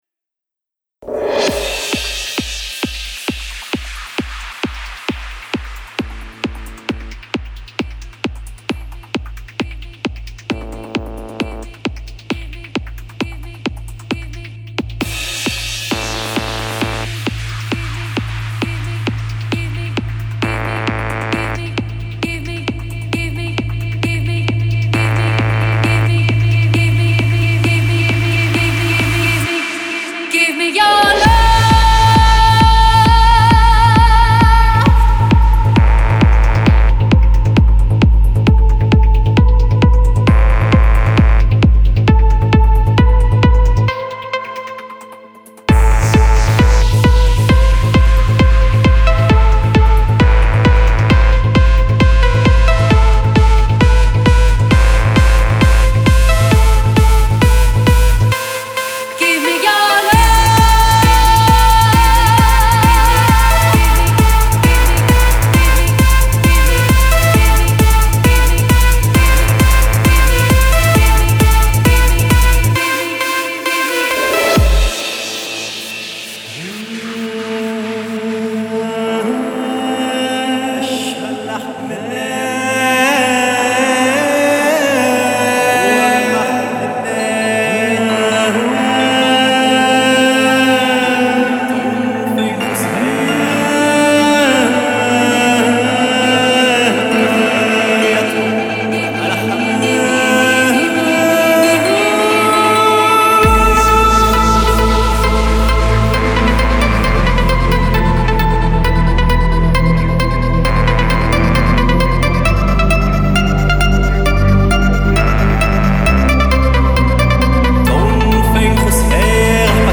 Genre : Techno